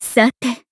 match-confirm.wav